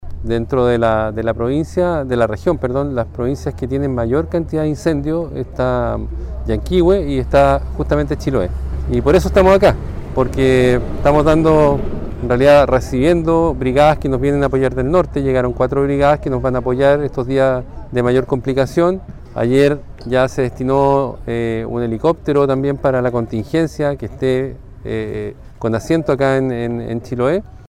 El Director regional de CONAF, Jorge Aichele, precisó que hasta el momento la isla de Chiloé registra un mayor número de incendios forestales en comparación al año pasado, cifrando en 12 los incendios que se mantienen activos. En ese contexto, Aichele informó que dentro de la Región de Los Lagos, las provincias que tienen más siniestros, son la de Llanquihue y Chiloé, dando cuenta además de la llegada de 40 brigadistas provenientes de la zona norte del país.